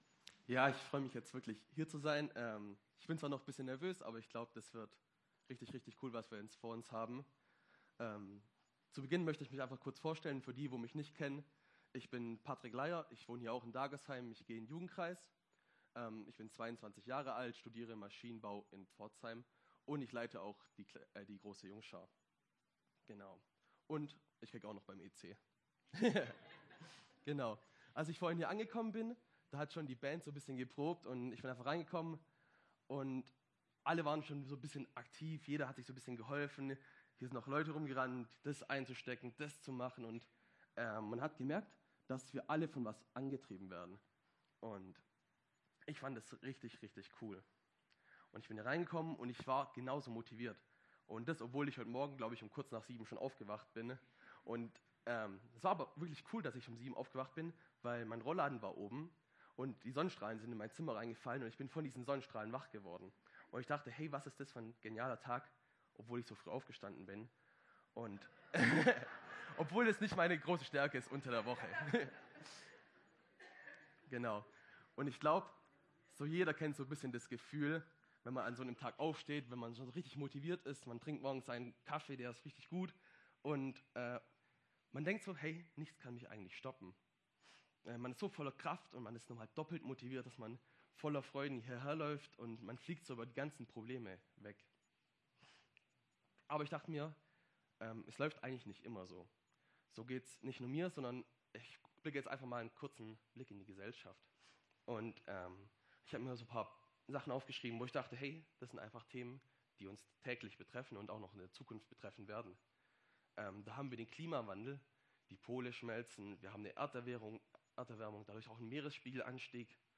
Sermon - SV Dagersheim/Darmsheim
A sermon from SV Dagersheim